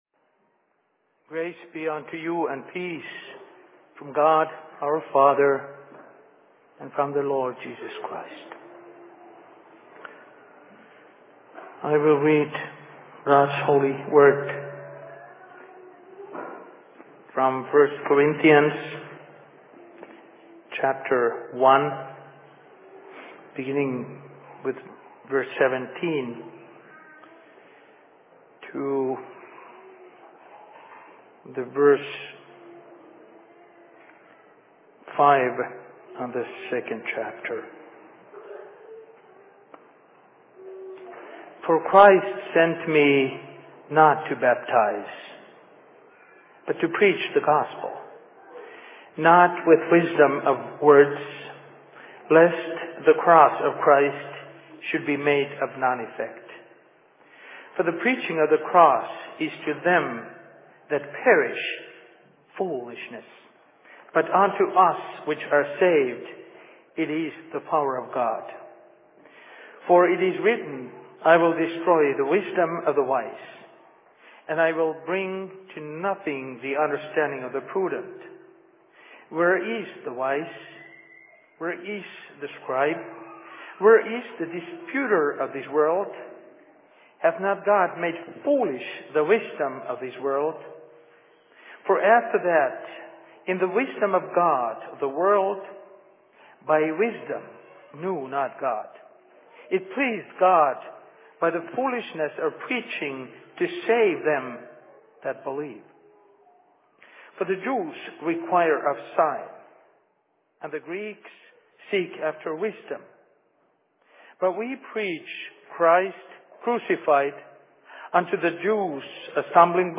Thanks Giving services/Sermon in Minneapolis 28.11.2008
Location: LLC Minneapolis